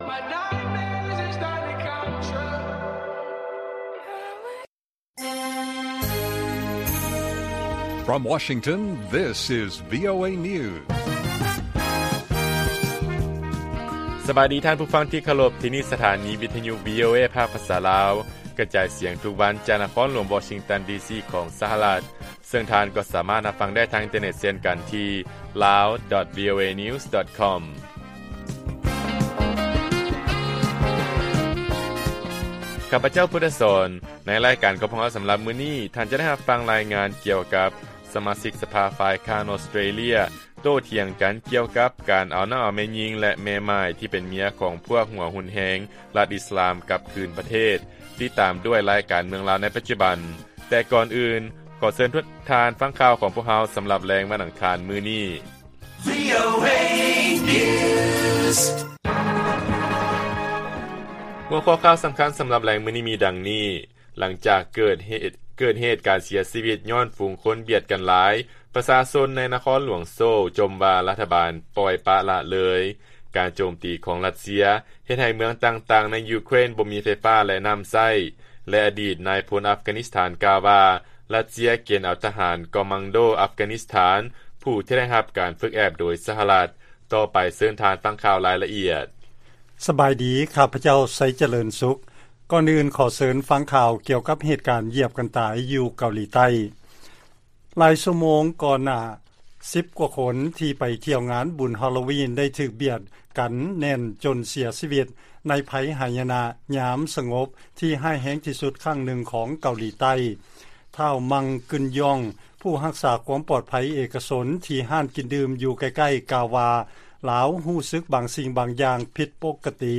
ລາຍການກະຈາຍສຽງຂອງວີໂອເອ ລາວ: ຫຼັງຈາກເກີດການເສຍຊີວິດ ຍ້ອນຝຸງຄົນບຽດກັນຫຼາຍ ປະຊາຊົນໃນໂຊລ ຈົ່ມວ່າ ລັດຖະບານ ປ່ອຍປະລະເລີຍ